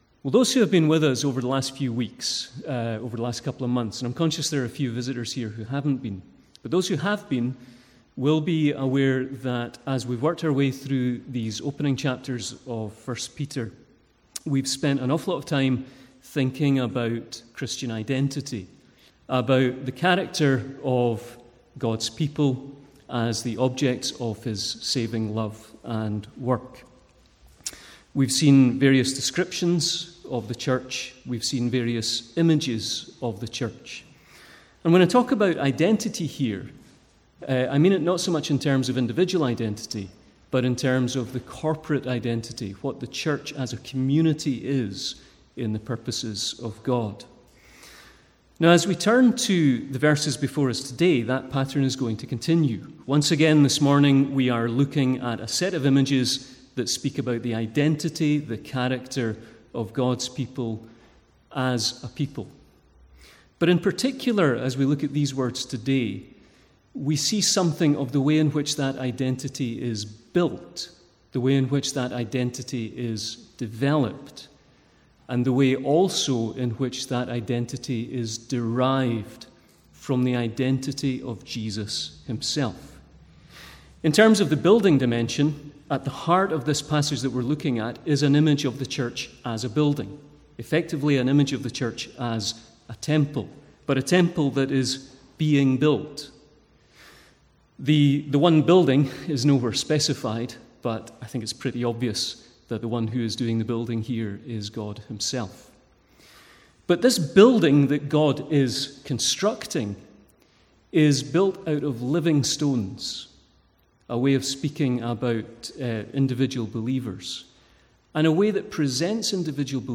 Sermons | St Andrews Free Church
From the Sunday morning series in 1 Peter.